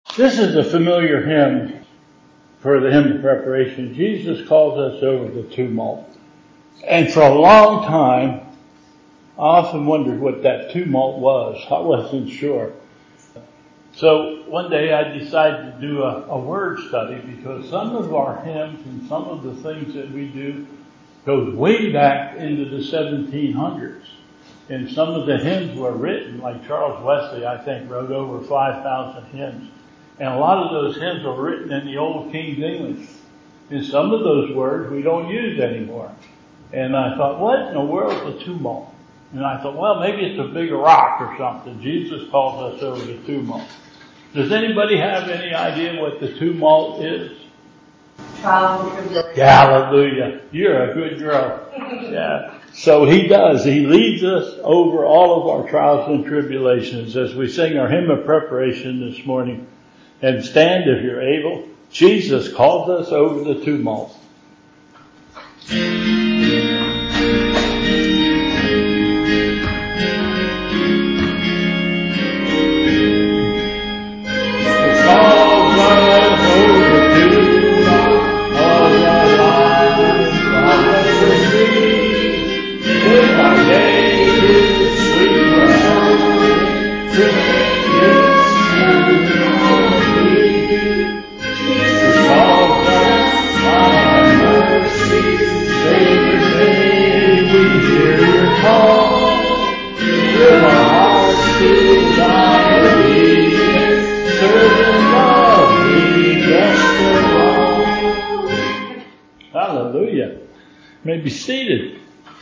Bethel Church Service
Hymn of Preparation